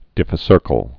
(dĭfĭ-sûrkəl)